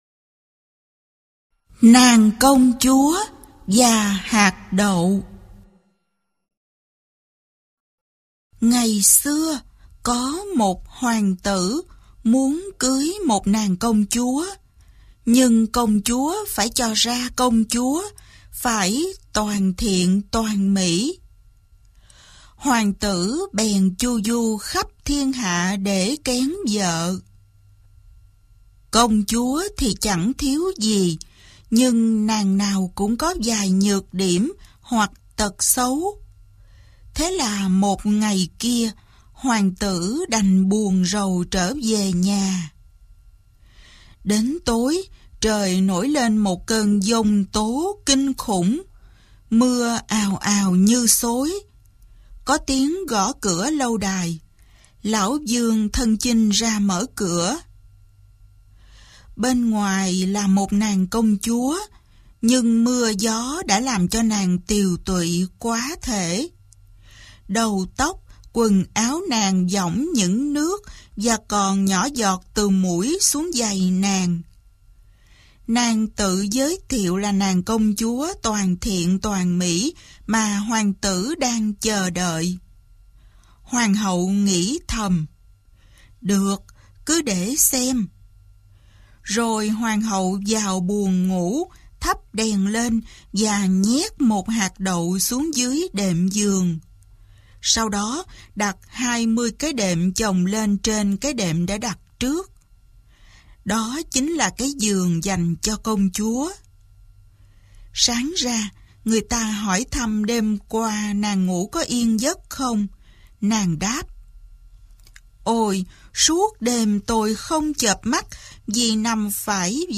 Sách nói | Nàng công chúa và hạt đậu